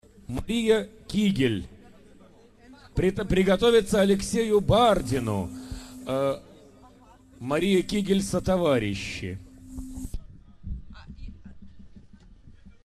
Белый слет 2003 г. Аудиоматериал